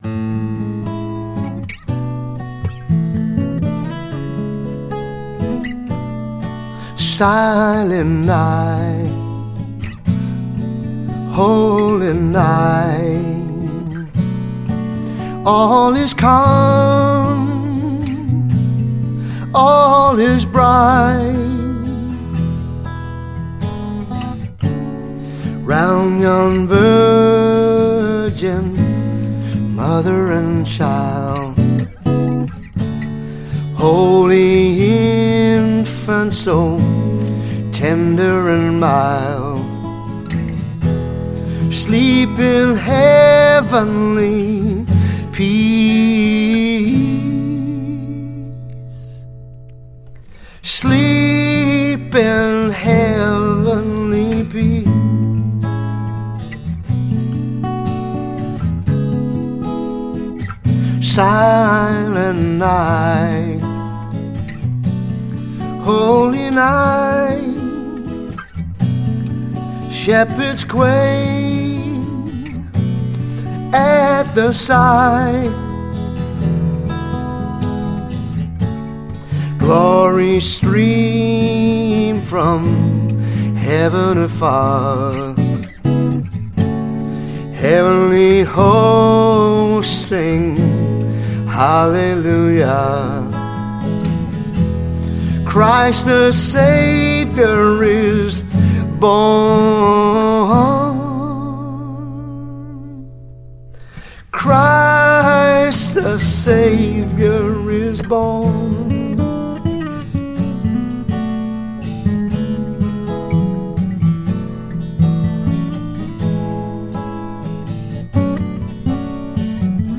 Christmas carols
R&B singer-composer and pastor from New Zealand.